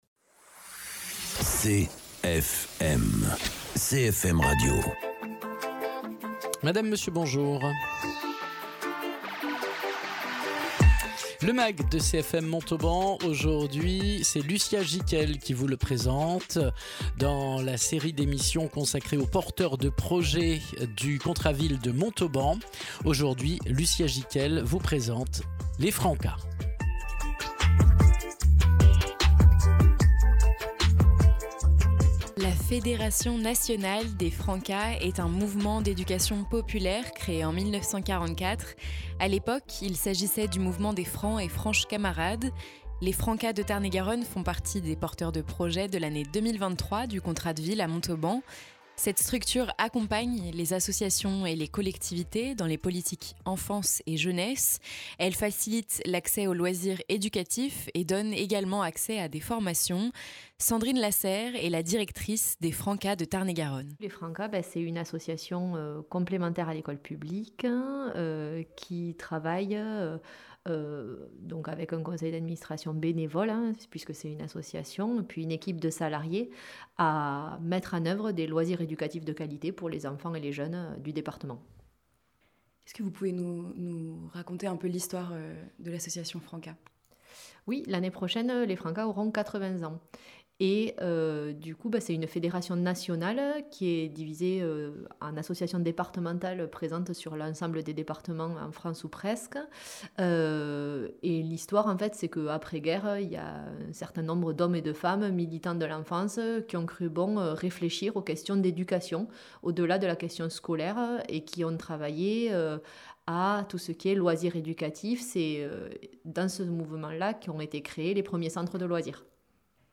Contrat de ville 2023 : reportage à propos des Francas de Tarn et Garonne